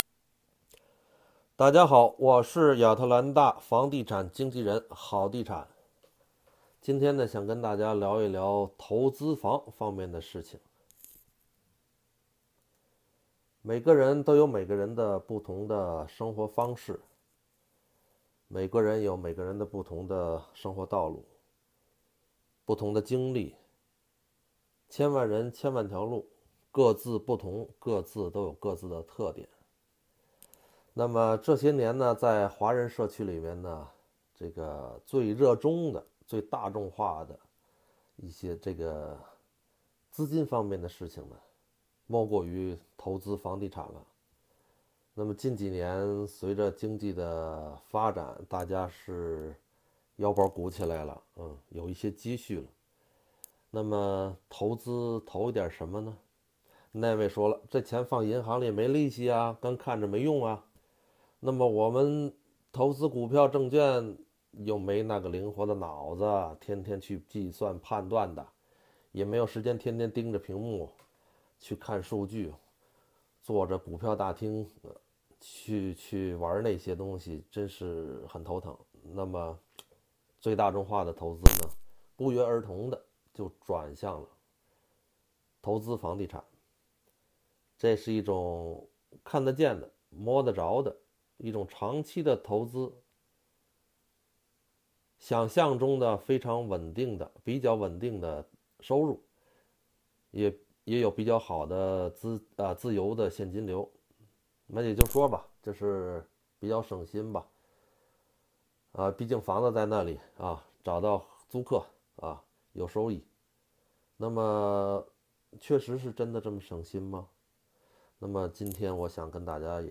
【语音讲座】投资房(1)